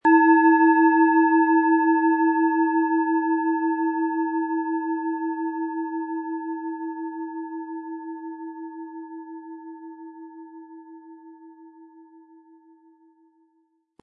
Planetenton 1
Hopi Herzton
Hopi-Herzton Klangschale von Hand gefertigt.
Das Klangbeispiel gibt den Ton genau dieser Schale wieder.